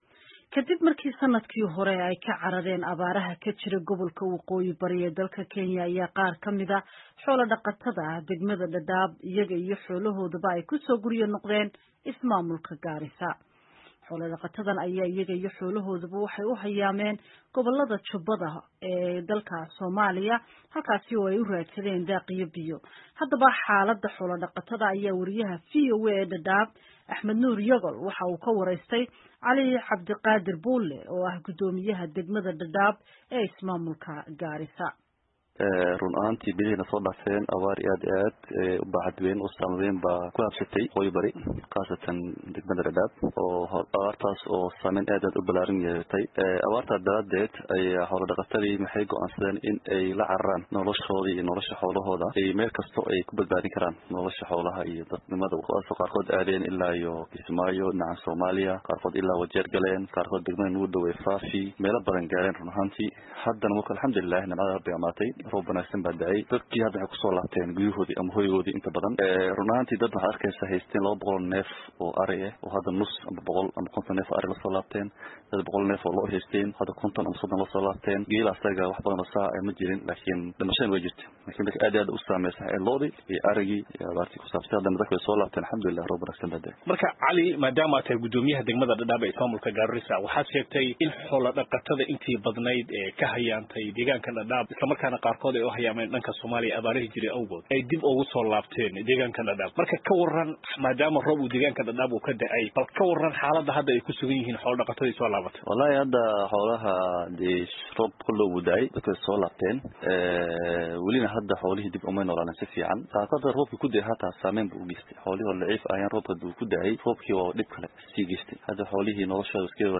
oo ah guddoomiyaha degmada Dhadhab ee Ismaamulka Gaarisa.